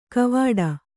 ♪ kavāḍa